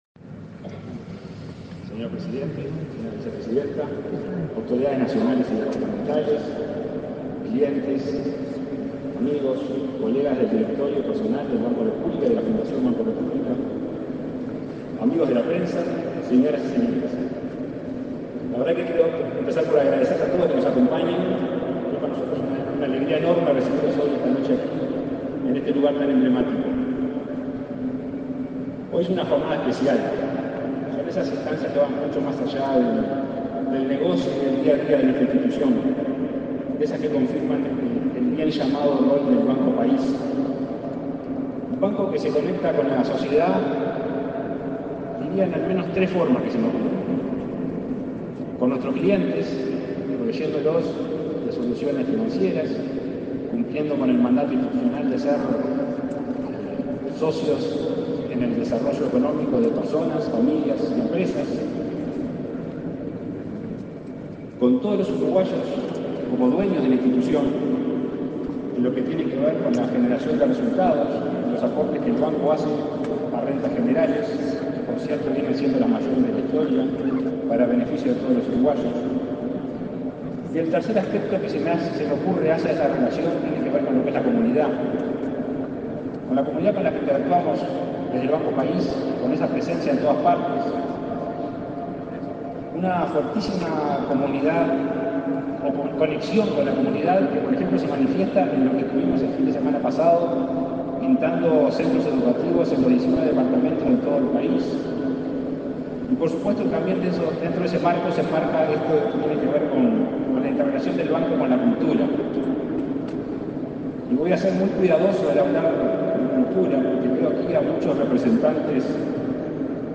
Acto de inauguración del Museo del Gaucho y la Moneda
Con la presencia del presidente de la República, Luis Lacalle Pou, se realizó, este 3 de octubre, la inauguración del Museo del Gaucho y la Moneda.
Disertaron en el evento el presidente del Banco República, Salvador Ferrer, y la subsecretaria del Ministerio de Educación y Cultura, Ana Ribeiro.